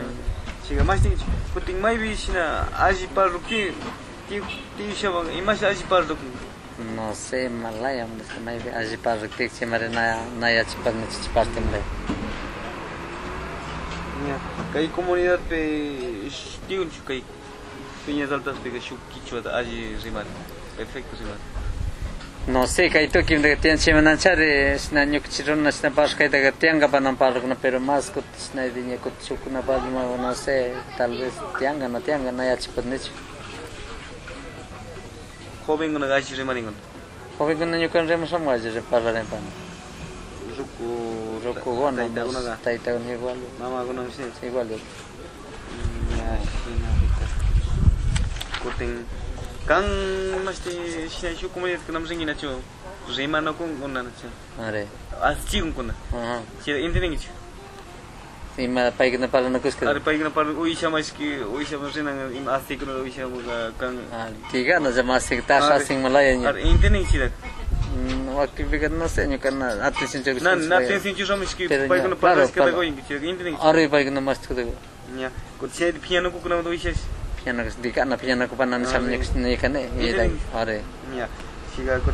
Entrevistas - San Cristóbal